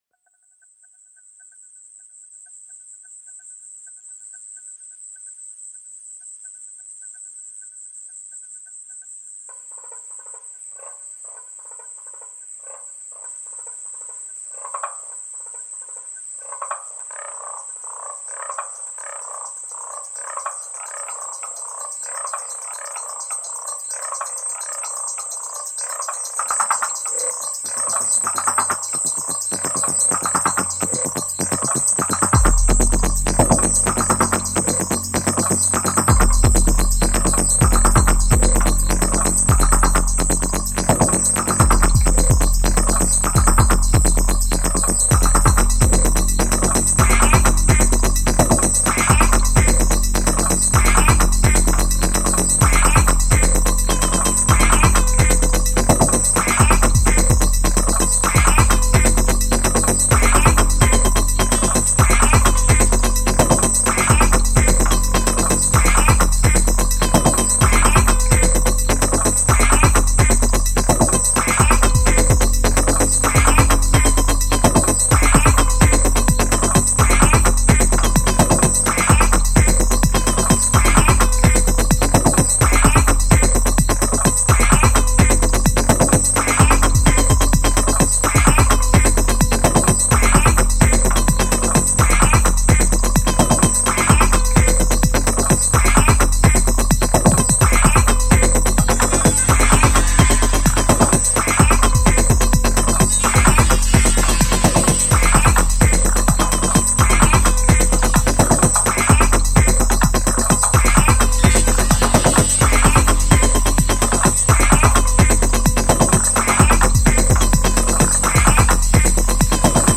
Hantana frog concerto